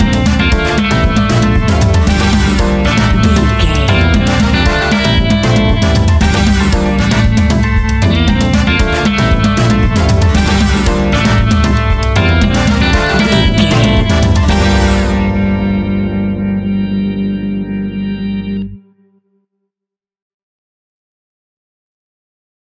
Aeolian/Minor
flamenco
latin
saxophone
trumpet
fender rhodes